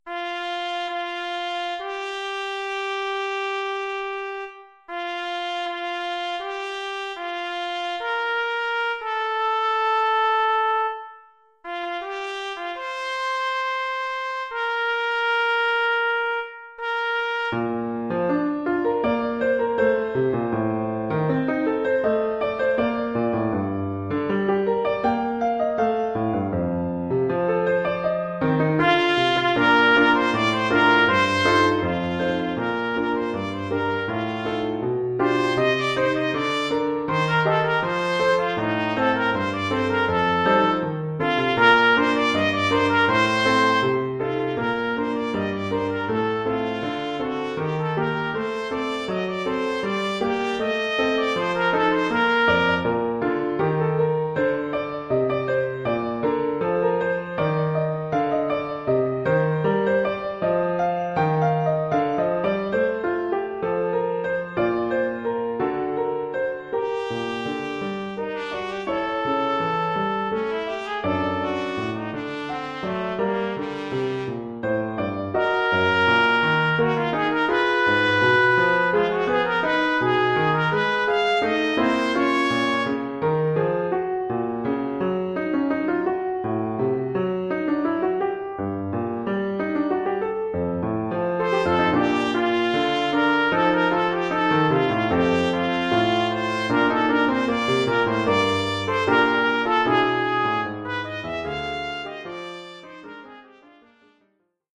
Oeuvre pour trompette ou cornet
ou bugle et piano.